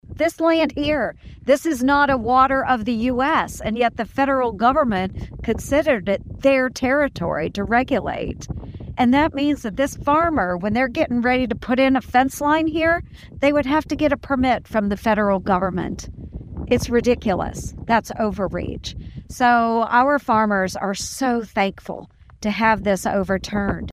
Ernst made her comments Friday in Willey.